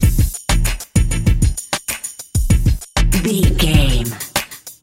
Aeolian/Minor
synthesiser
drum machine
hip hop
Funk
neo soul
acid jazz
energetic
bouncy
Triumphant
funky